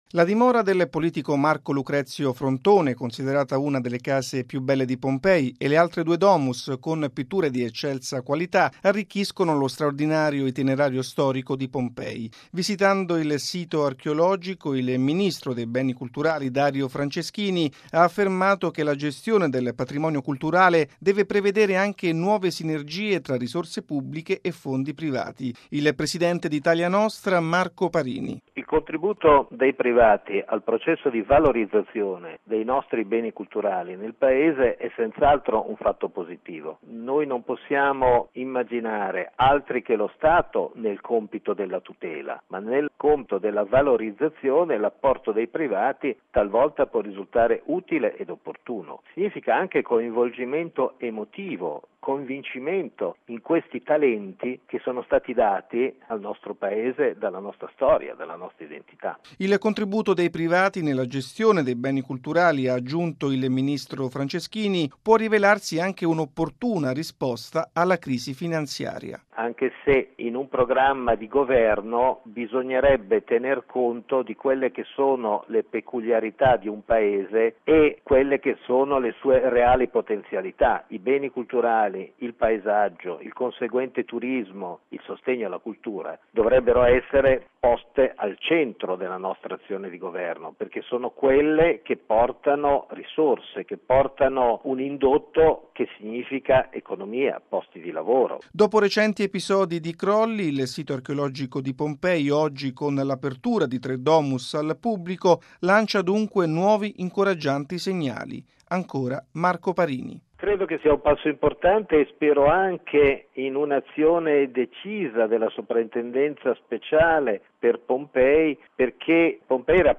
All’inaugurazione è intervenuto il ministro dei Beni Culturali, Dario Franceschini. Il servizio